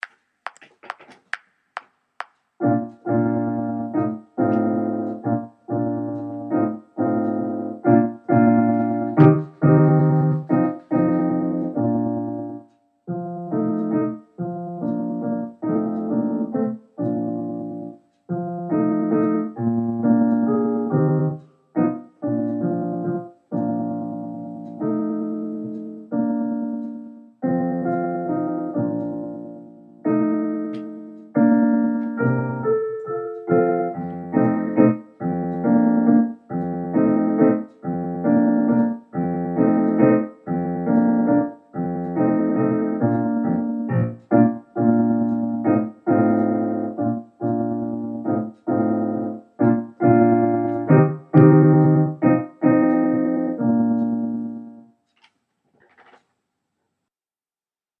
Concertpiece Piano Part